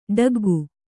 ♪ ḍaggu